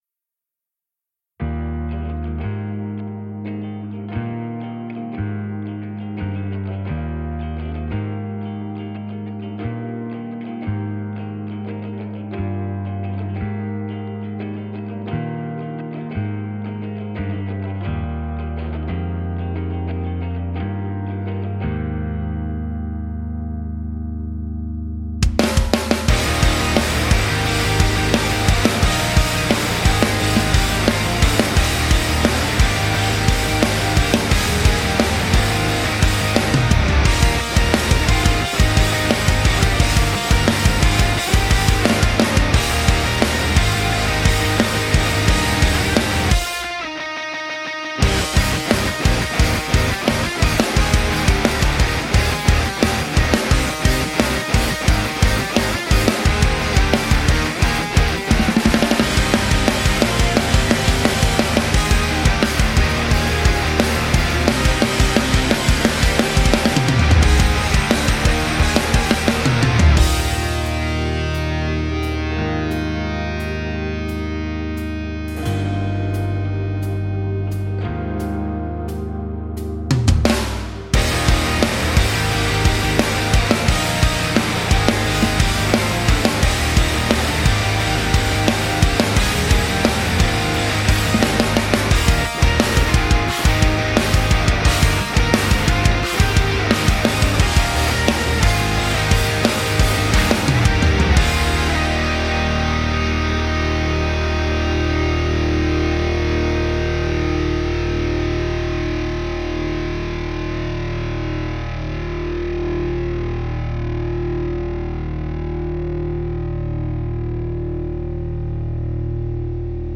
- 它只包含一套完美调音的鼓组，包括一个大鼓、一个小鼓、两个军鼓、一个镲铜和五个钹。
- 它的鼓声富有活力和个性，适合制作高能量的流行朋克音乐，也可以用于其他现代风格的音乐。